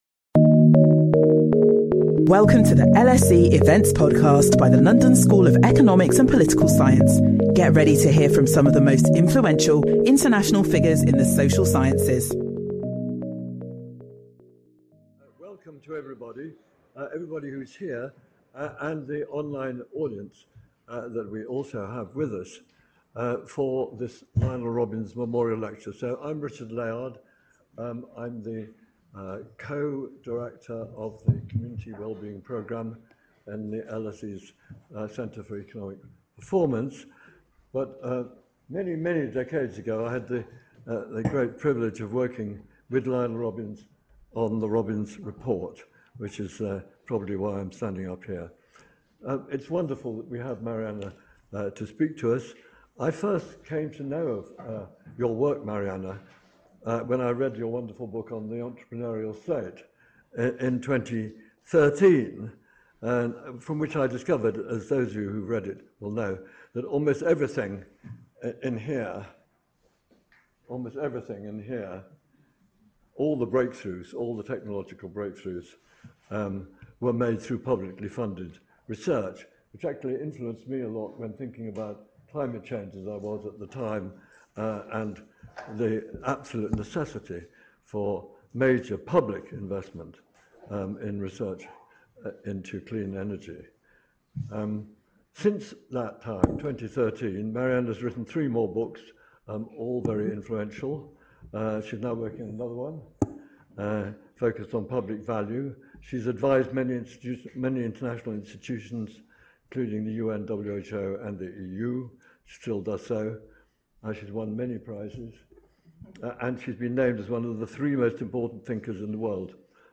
Mariana Mazzucato delivers this lecture as part of the Lionel Robbins Lecture Series.